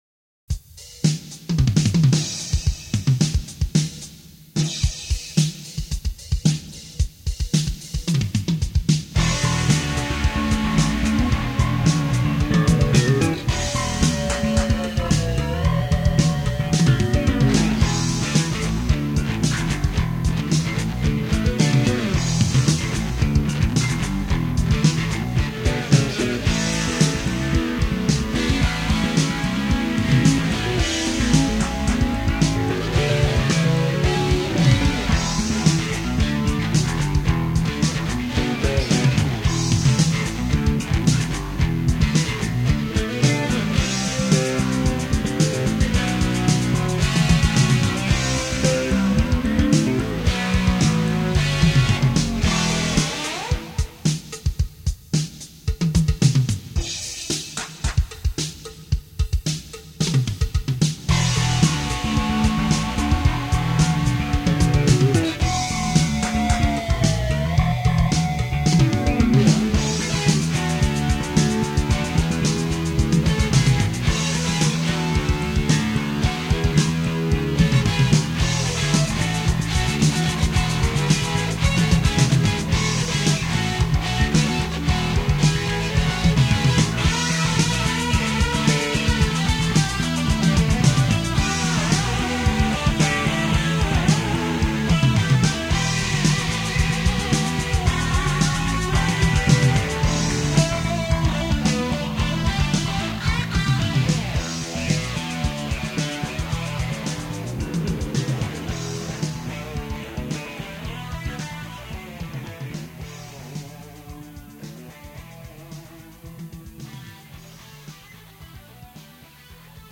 Zwangsläufig ergibt sich auch dabei (trotz DBX-Rauschunterdrückung) ein deutliches Maß an Bandrauschen und Abmischfehlern.
Das Schlagzeug wurde durch "Electronic Drums" realisiert.
ein Instrumental-Stück, lebt von seiner äußerst sparsamen Instrumentierung:
Bass, eine Gitarre, Schlagzeug.
Wie der Name des Titels erahnen lässt, steht dabei der Bass im Vordergrund.